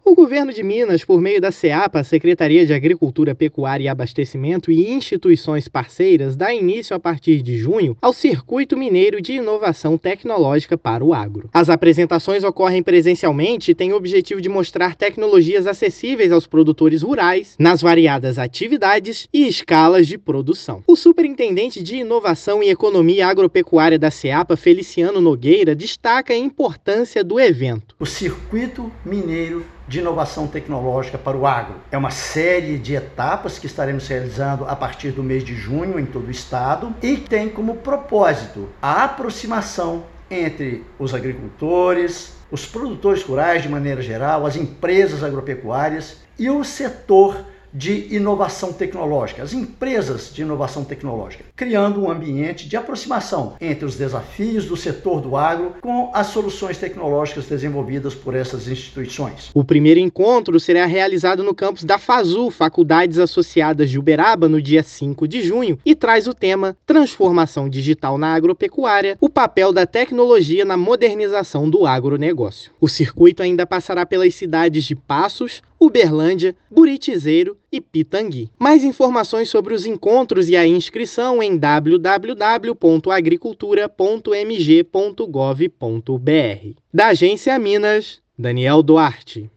[RÁDIO] Governo de Minas apresenta soluções em inovação tecnológica para o agro
Primeira etapa do Circuito Mineiro será em 5/6 e apresenta novas formas de manejo em atividades agropecuárias empresariais e familiares. Ouça a matéria de rádio: